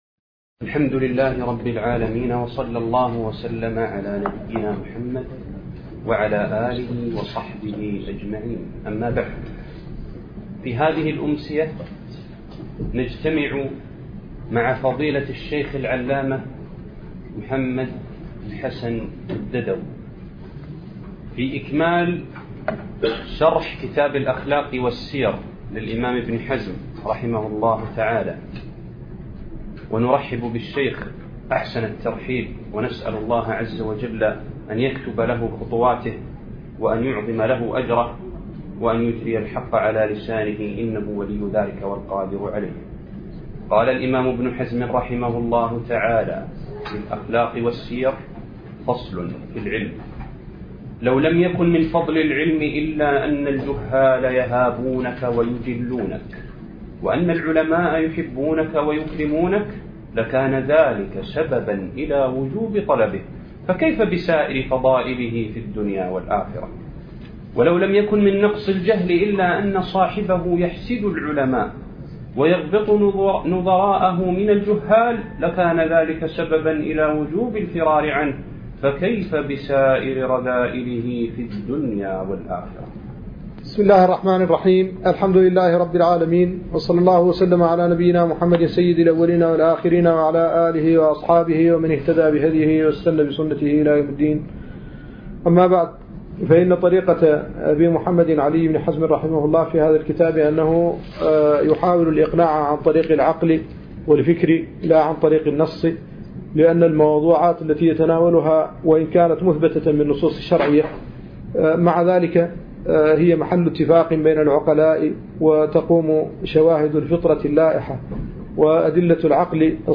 الدرس السادس عشر